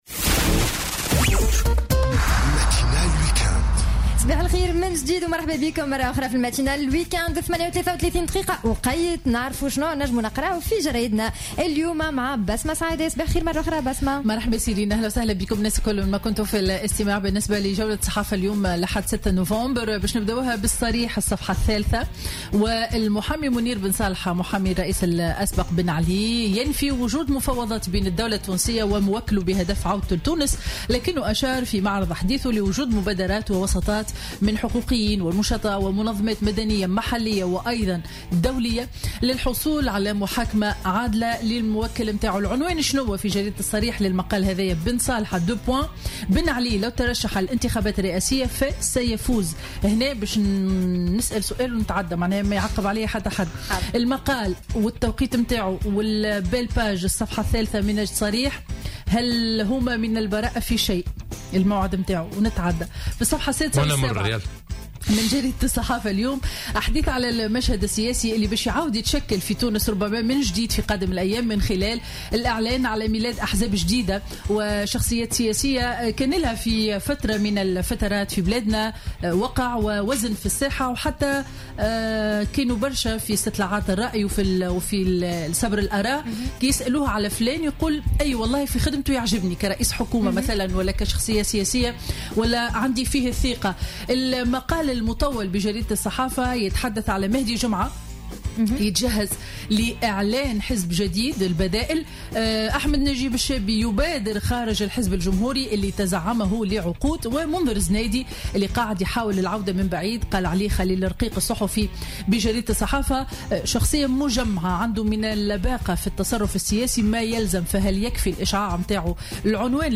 Revue de presse 06/11/2016 à 09:24